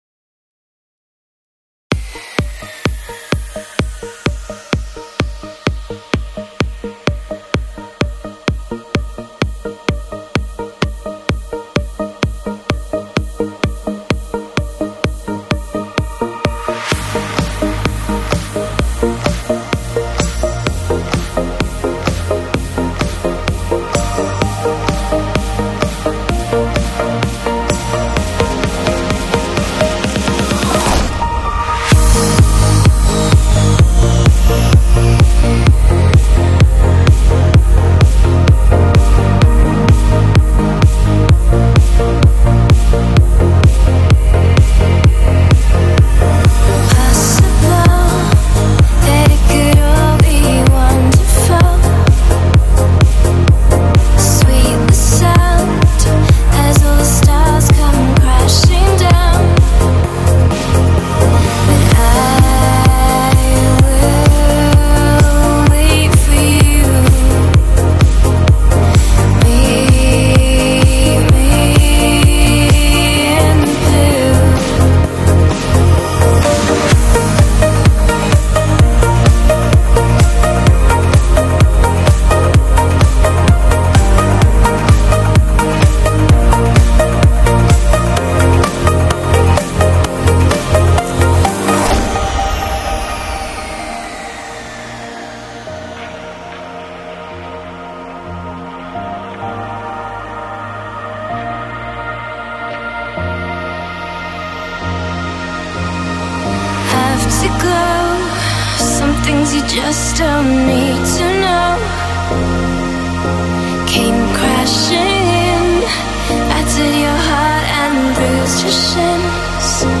Стиль: Progressive Trance / Vocal Trance